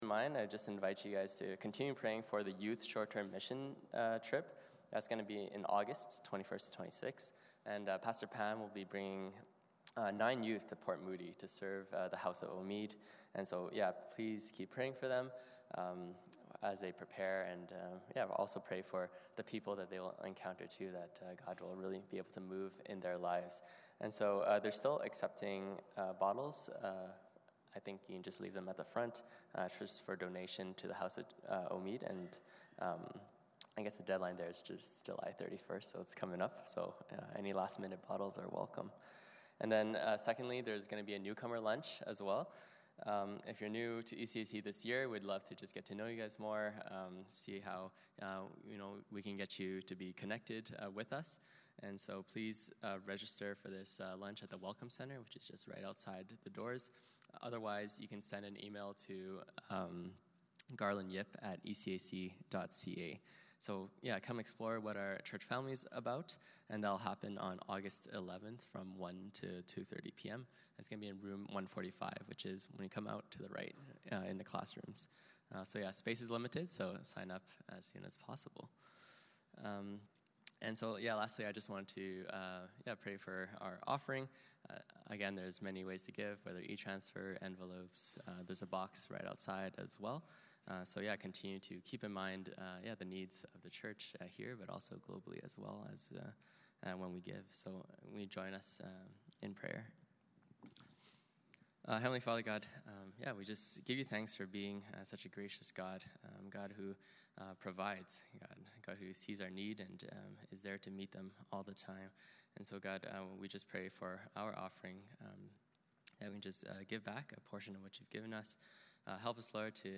12-26 Service Type: Sunday Morning Service Passage